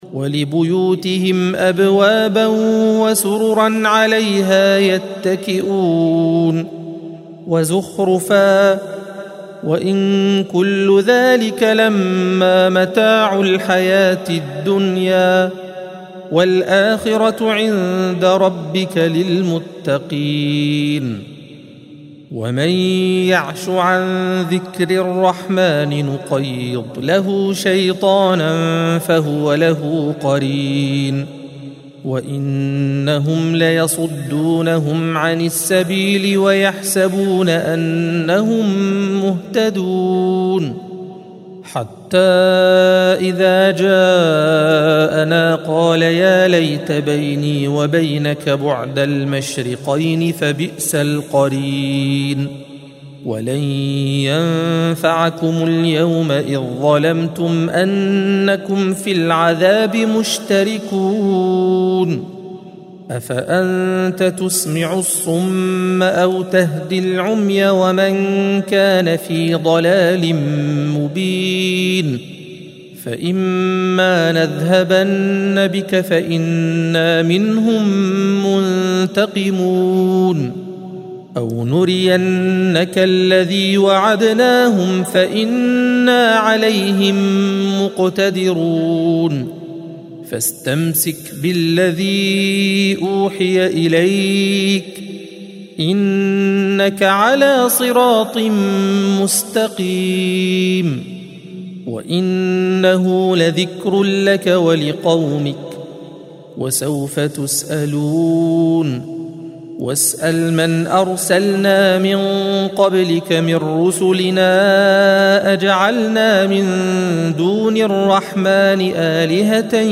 الصفحة 492 - القارئ